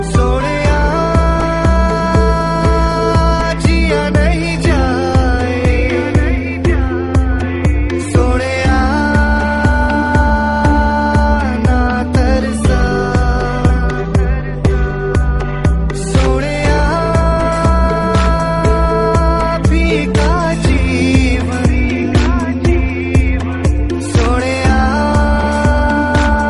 love song Category